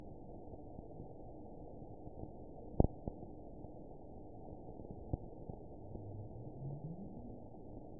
event 920089 date 02/22/24 time 07:51:02 GMT (1 year, 9 months ago) score 9.28 location TSS-AB01 detected by nrw target species NRW annotations +NRW Spectrogram: Frequency (kHz) vs. Time (s) audio not available .wav